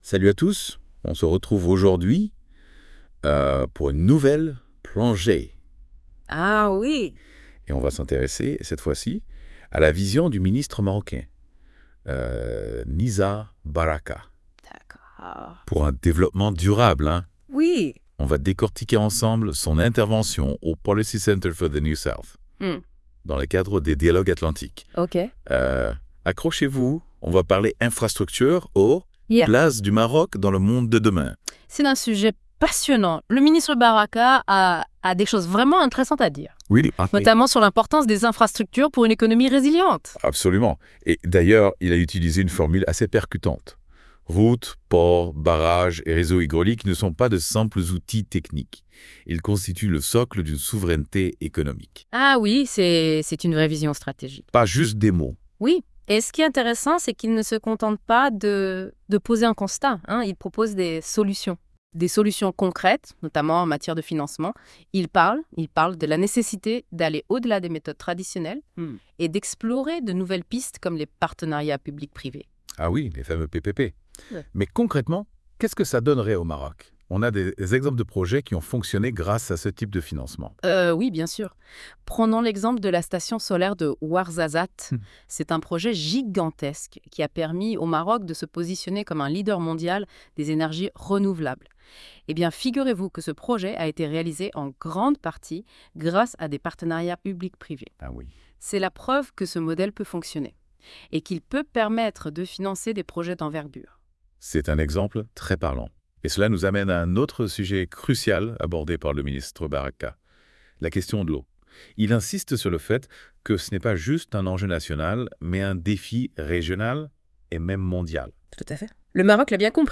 Débat à écouter (38.92 Mo)